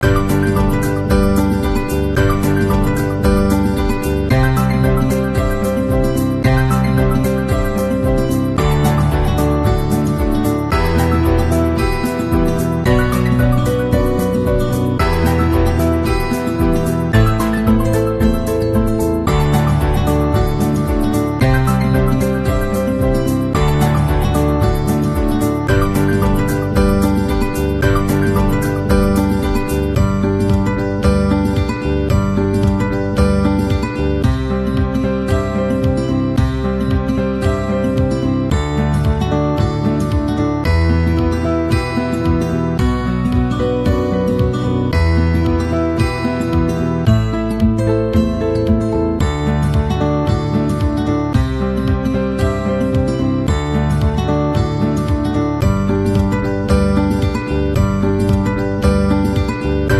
Open And Close A Wine Sound Effects Free Download